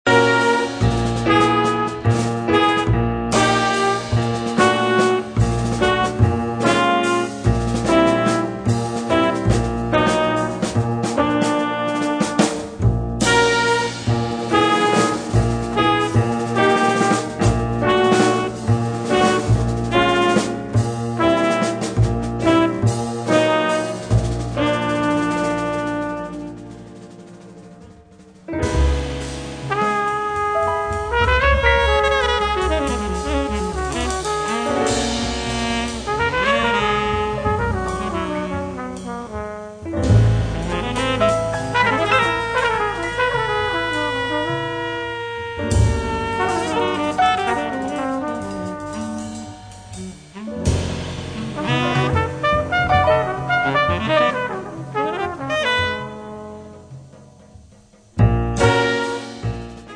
piano
basso
batteria
sax tenore
Tromba